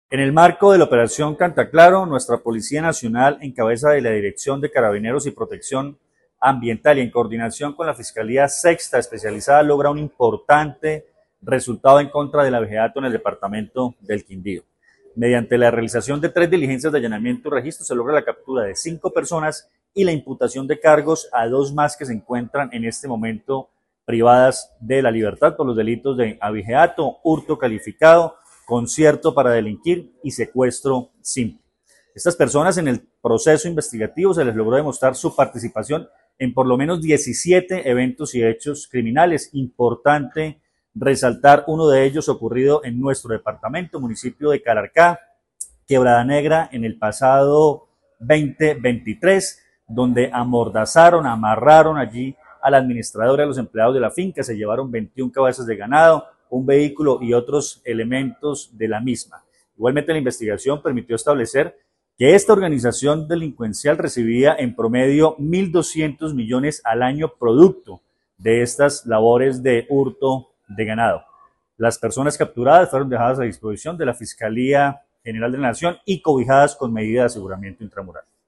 Comandante de la Policía del Quindío, coronel Luis Fernando Atuesta